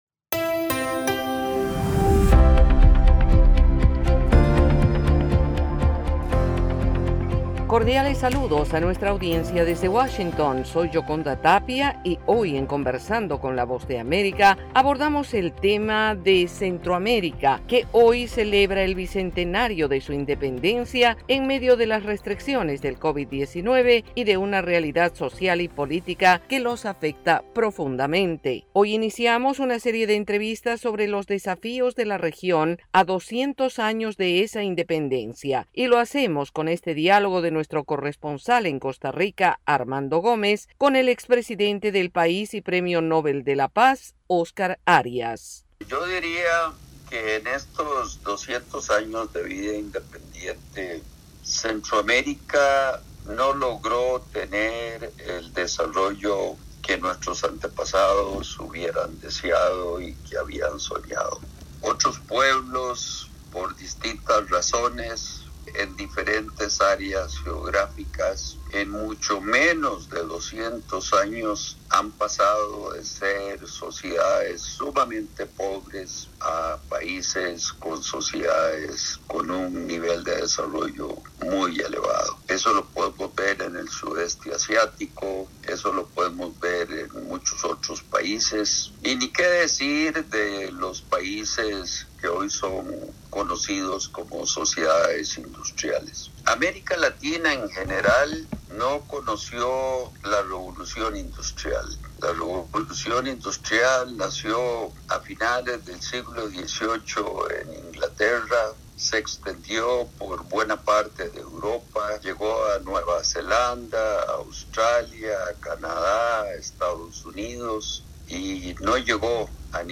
Conversamos con el expresidente de Costa Rica y premio Nobel de la Paz, Oscar Arias, destacando los retos que se deben asumir en Centroamérica al recordar los 200 años de su Independencia.